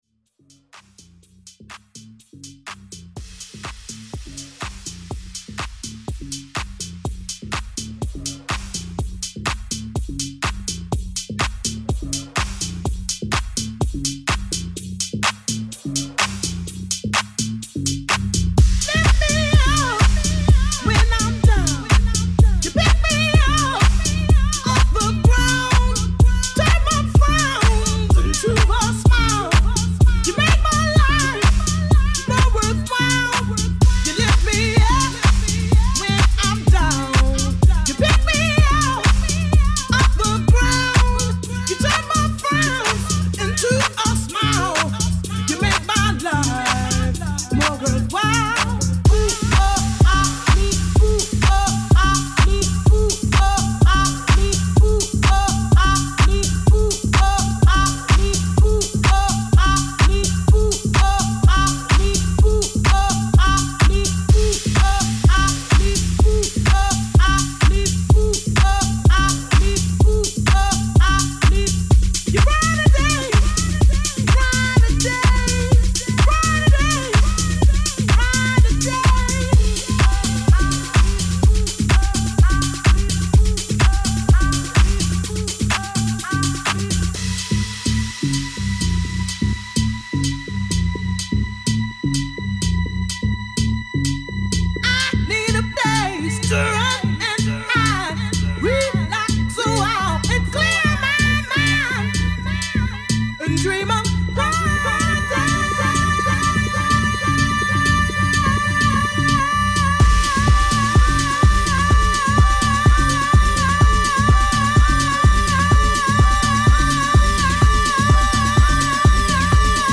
Funky house , Tribal house